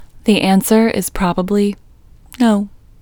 OUT Technique Female English 23